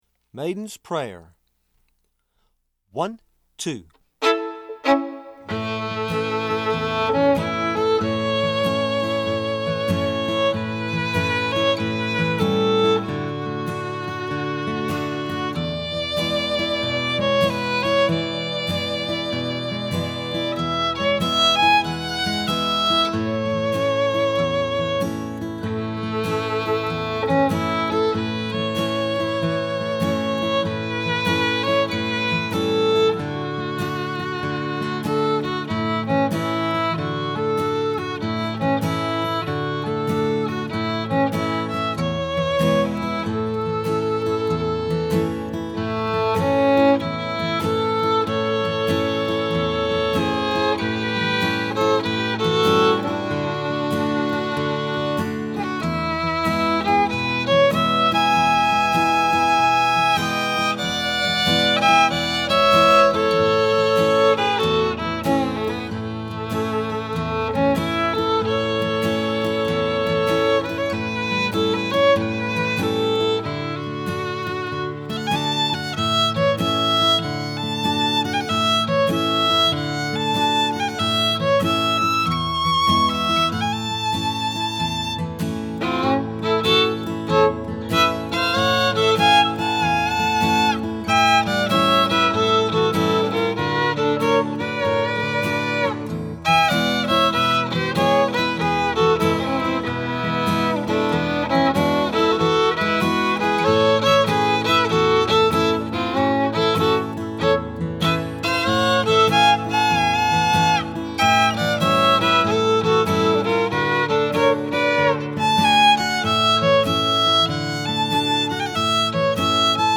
FIDDLE SOLO Fiddle Solo, Traditional
DIGITAL SHEET MUSIC - FIDDLE SOLO